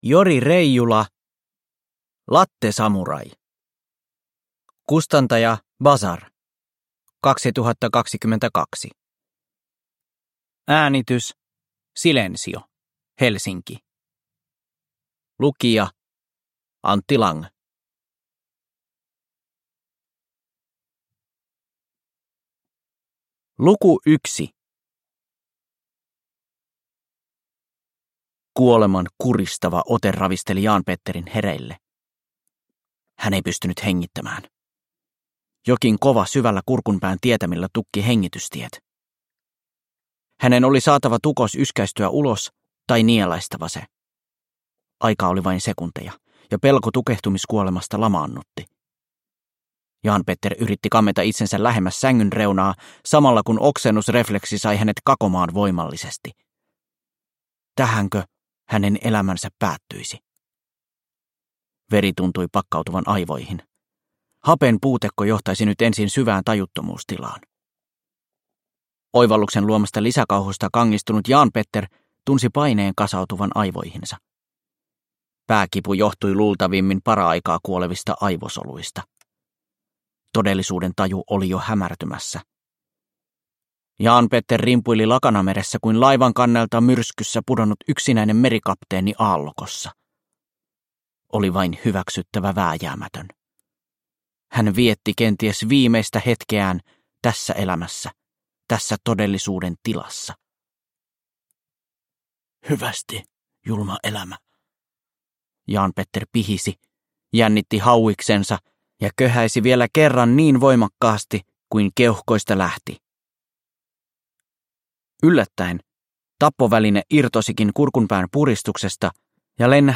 Lattesamurai – Ljudbok – Laddas ner